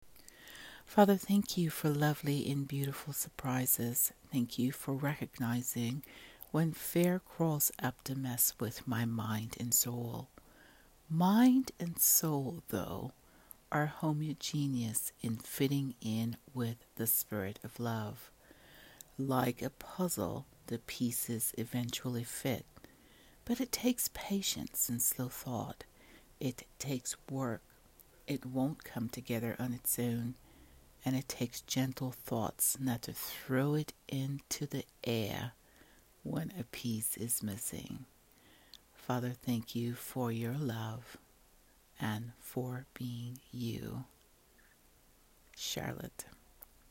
Spoken words: